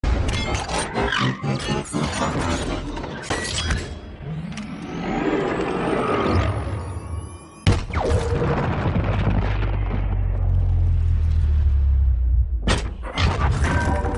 auto-transforming_24908.mp3